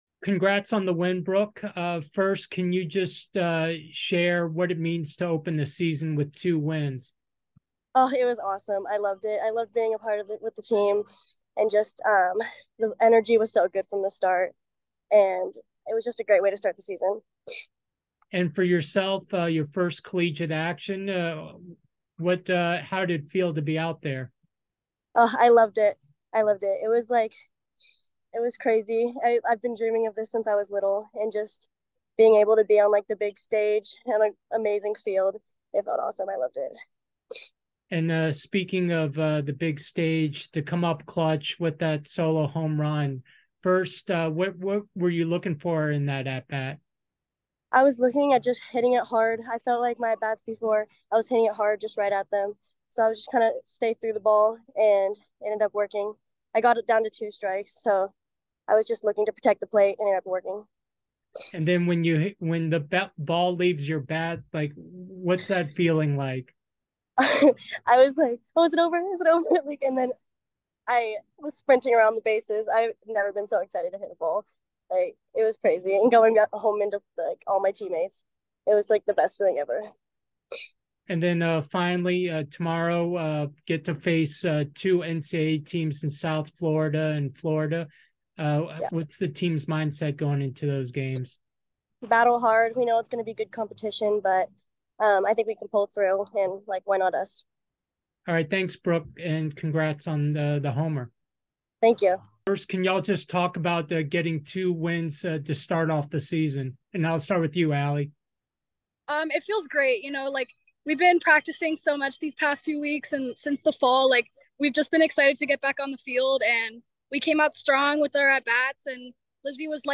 SJU-ISU Postgame Interview